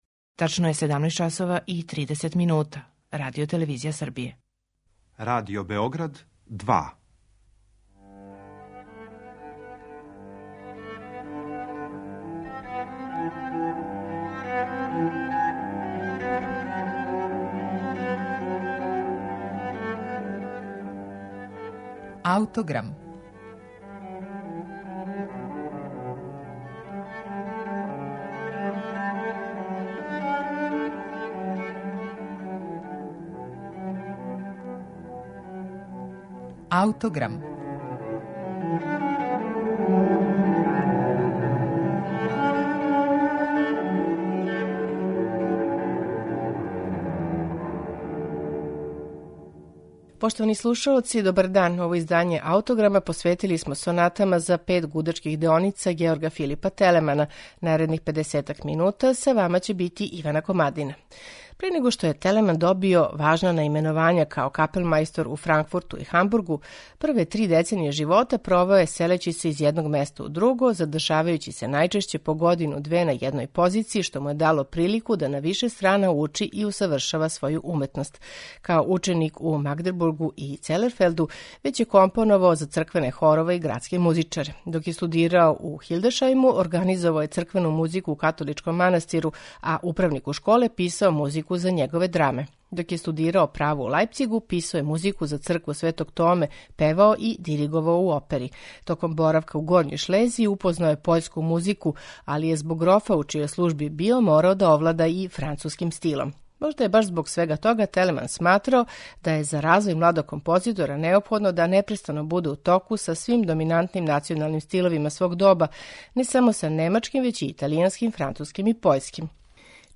Четири сонате за пет гудачких деоница, којима смо посветили данашњи Аутограм, у пуној мери сведоче о оваквом његовом ставу, показујући истовремено висок ниво познавања различитих композиционих стилова којим је Телеман владао и као релативно млад аутор.
Четири сонате за пет гудачких деоница Георга Филипа Телемана слушаћете у интерпретацији чланова ансамбла Freiburger Barockorchester Consort .